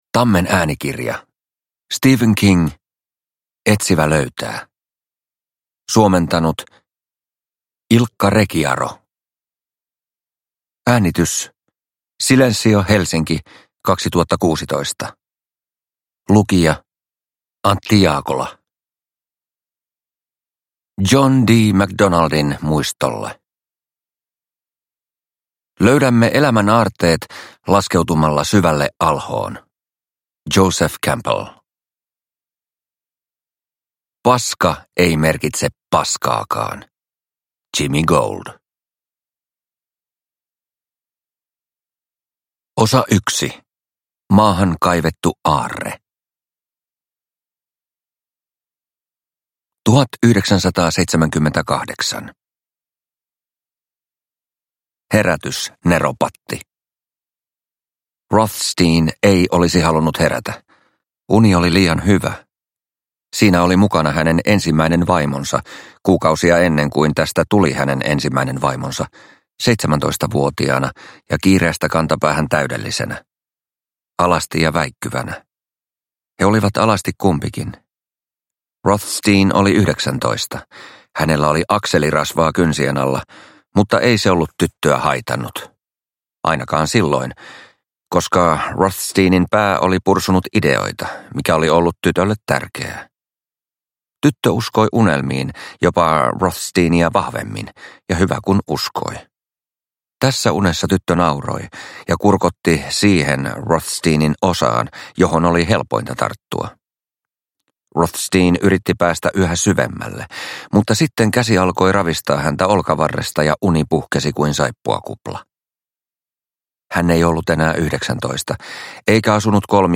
Etsivä löytää – Ljudbok – Laddas ner